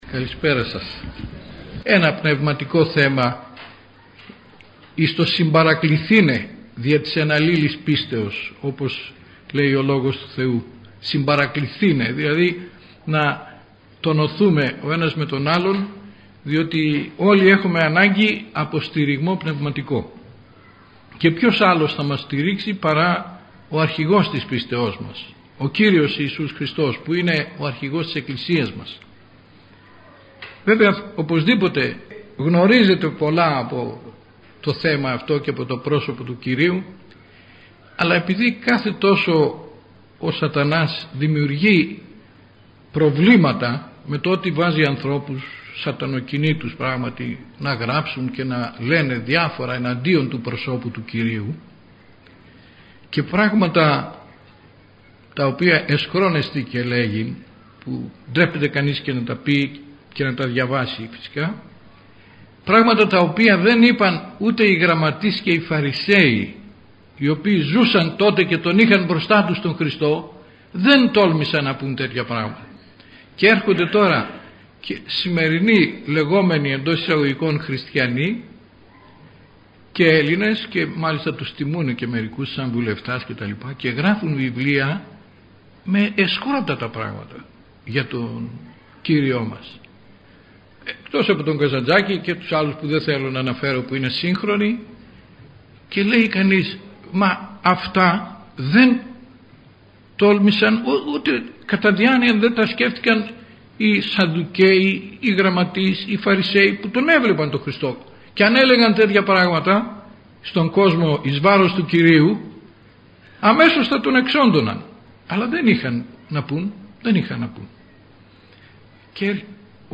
Η ομιλία αυτή πραγματοποιήθηκε στην μεγάλη αίθουσα της Χριστιανικής ενώσεως Αγρινίου.